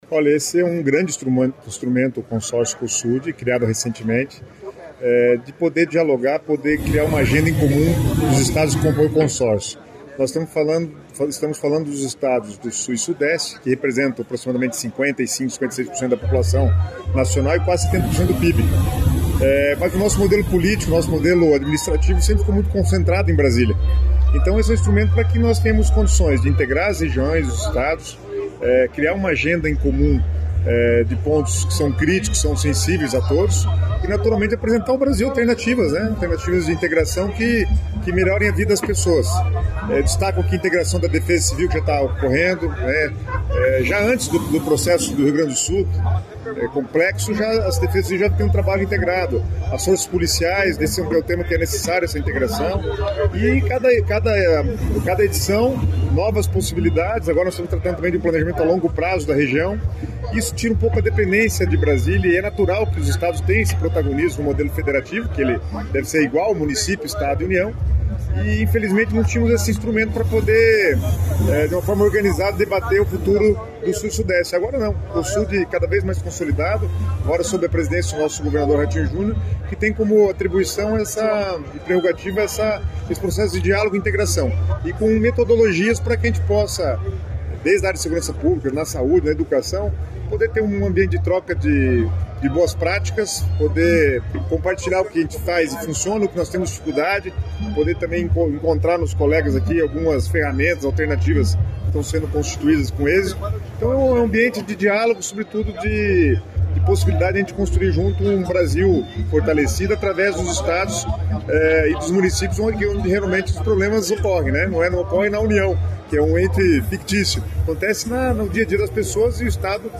Sonora do secretário Estadual do Planejamento, Guto Silva, sobre a abertura do 11° encontro do Cosud